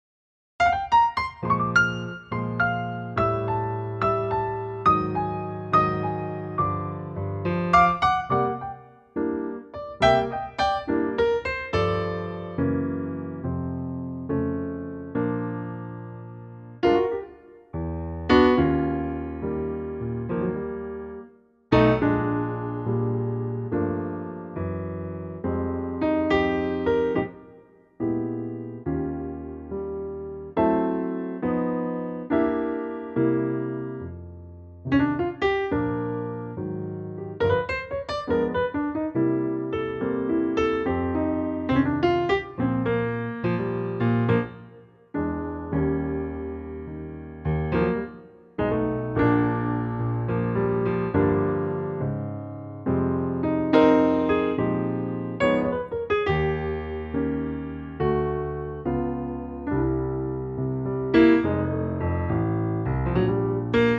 key - Bb - vocal range - F to G
Wonderful piano only arrangement